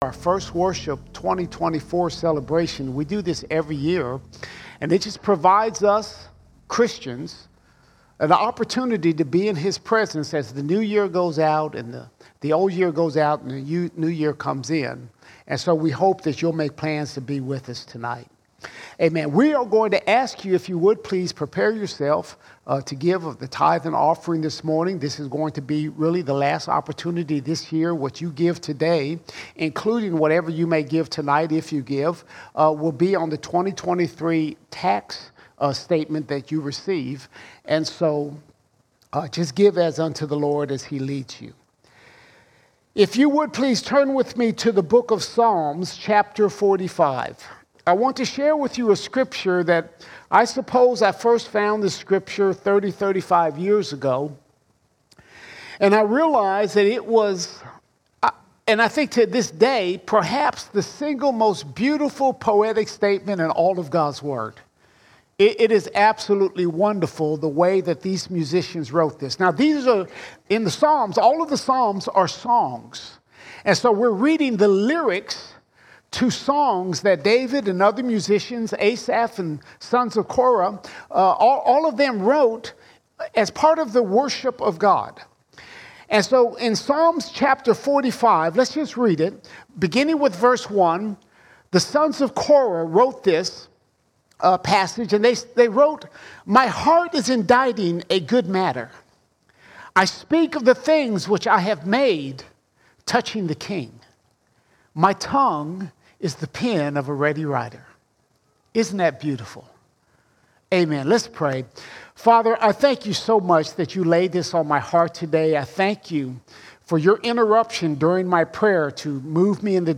2 January 2024 Series: Sunday Sermons Topic: worship All Sermons Our Heart, Worship, & Tongues Our Heart, Worship, & Tongues Do you know the difference between praise and worship?